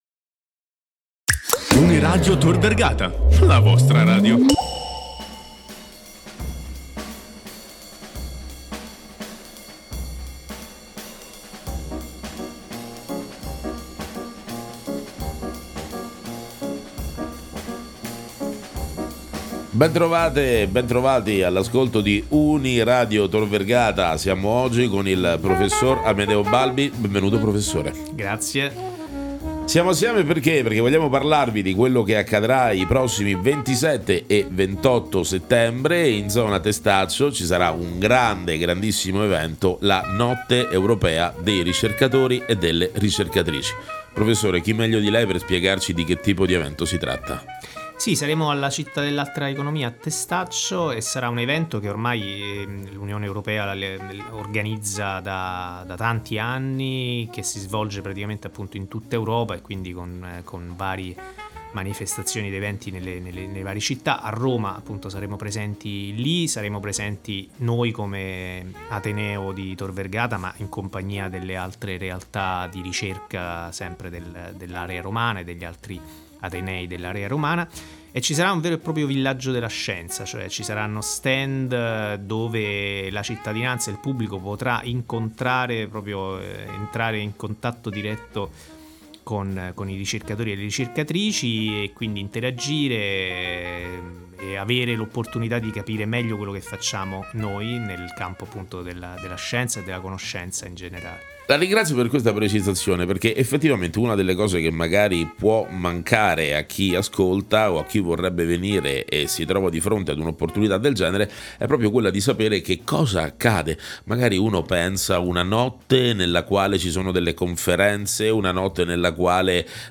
Uniradio Tor Vergata partecipa alla Notte Europea dei Ricercatori e delle Ricercatrici 2024, un incontro per conoscere, confrontarsi, apprendere e per far avvicinare tanti giovani alle discipline di vari settori della ricerca presso la Città dell’Altra Economia a Testaccio.
Intervista